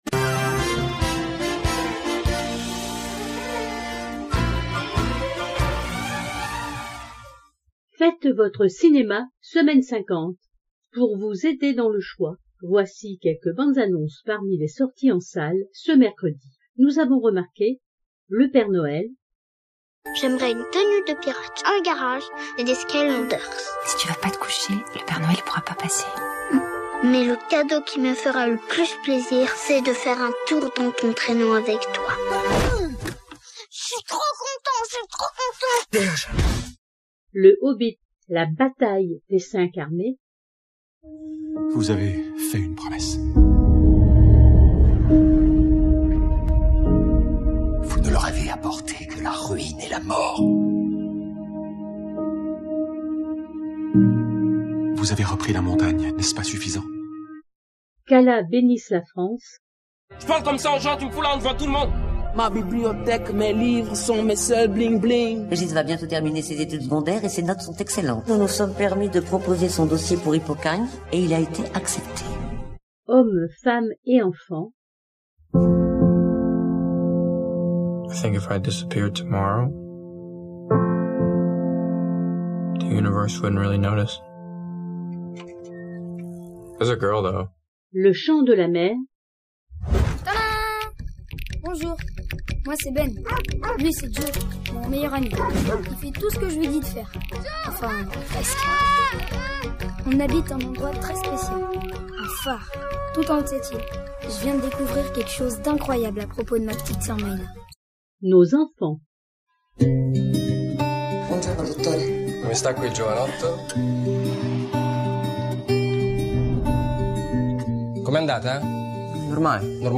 Extraits des films sortis cette semaine